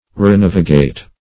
Renavigate \Re*nav"i*gate\ (r?-n?v"?-g?t)